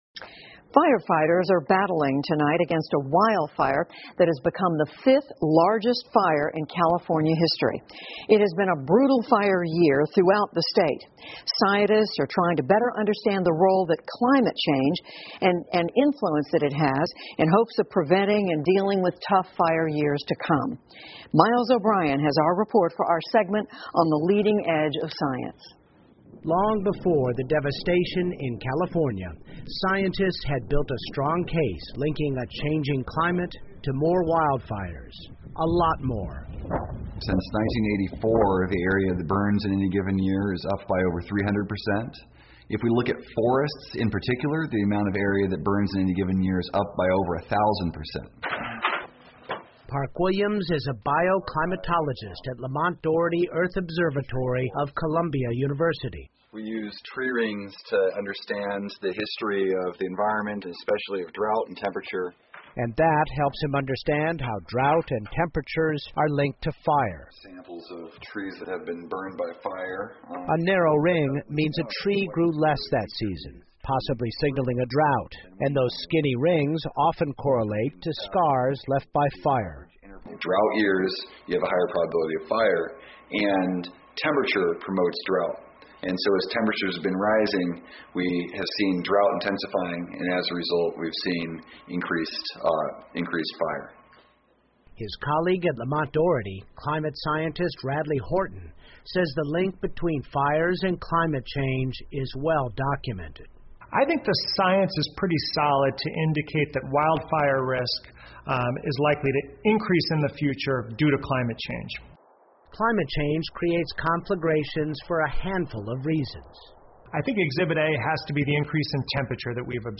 PBS高端访谈:气候变化是加州大火的罪魁祸首之一 听力文件下载—在线英语听力室